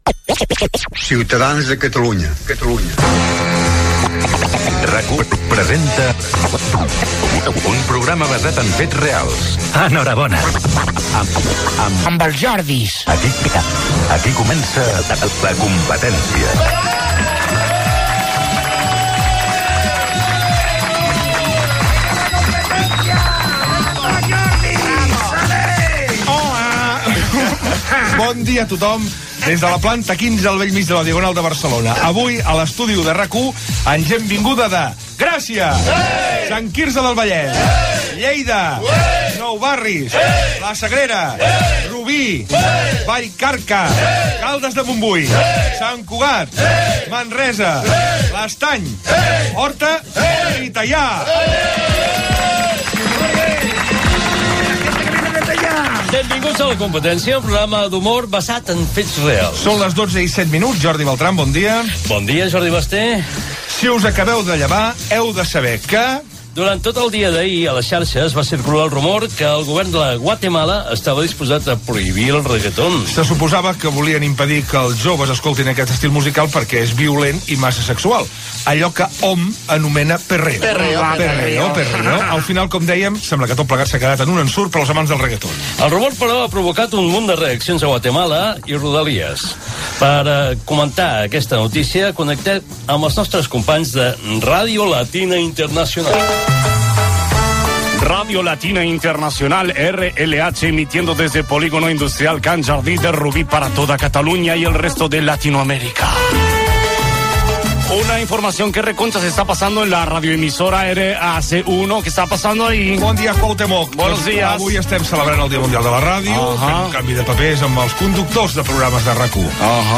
Careta del programa, presentació amb esment a les localitats del públic assistent a l'estudi, connexió amb "Radio Latina Internacional" i Guatemala, l'Assemblea Nacional de Catalunya, indicatiu del programa
Entreteniment